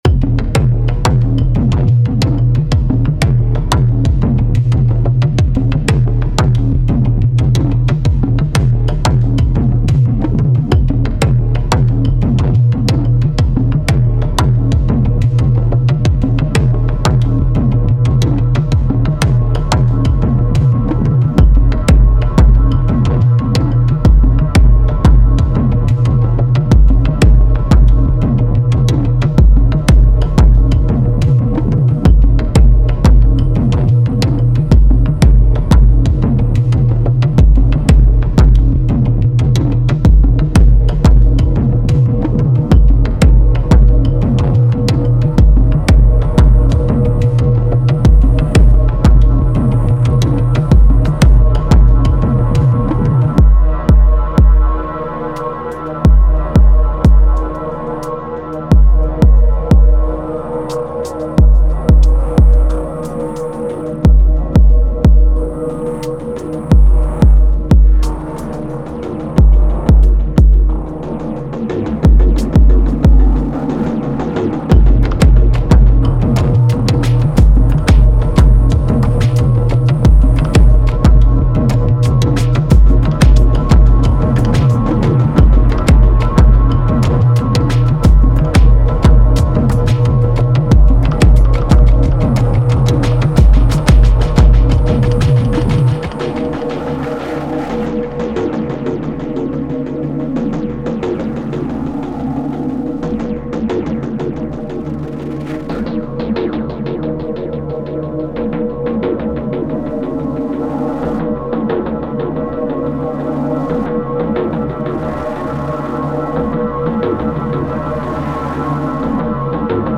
Didn’t dwell on it to too long and just made a short jam, didn’t dwell on it for too long and recorded the jam so I could share :smiling_face:
maine structure is tonverk, supported by digitakt and digitone, and yeah heat fx